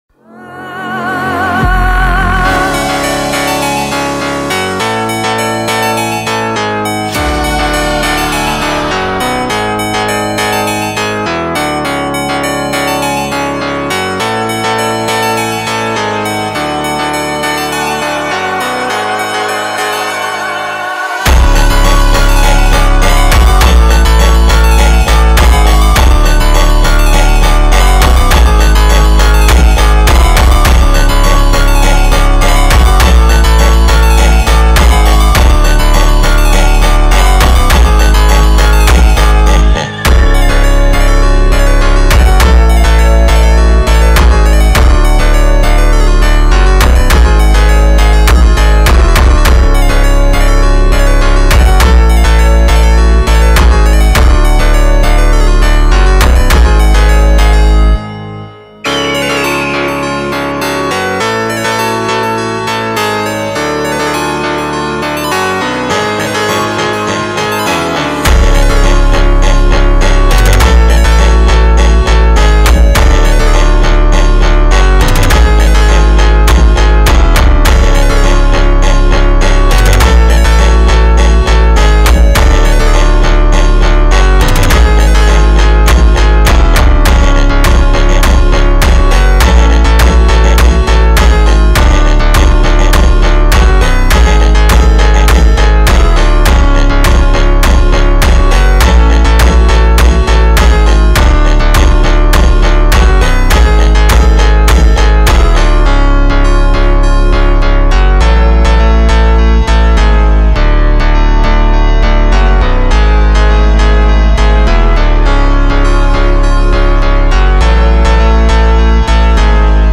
Скачать музыку / Музон / Speed Up